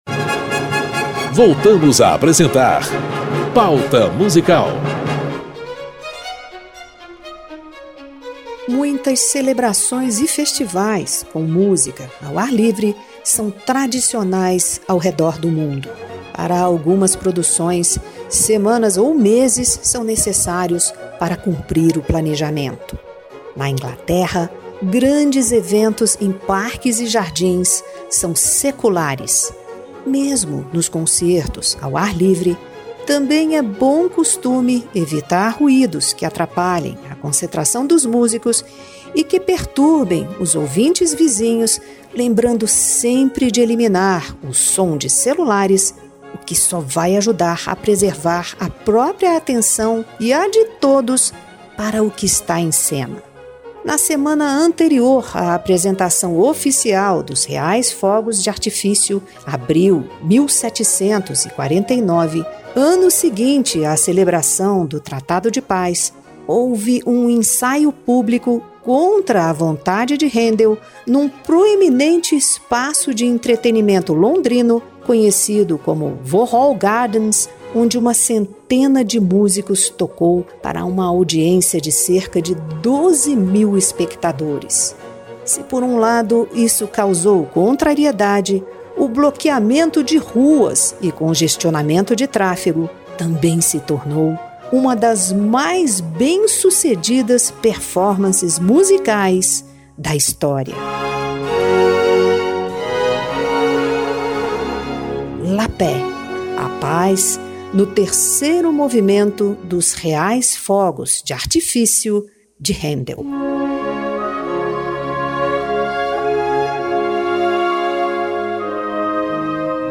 Um brinde ao novo ano! A música de Händel para os Reais Fogos de Artifício e o tradicional estilo vienense das valsas de Strauss, para celebrar a paz e as boas entradas.